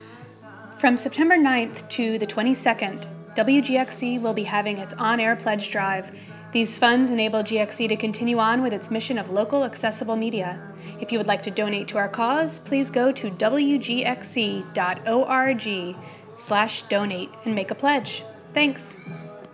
An Official WGXC PSA for WGXC's fall 2012 Pledge Drive.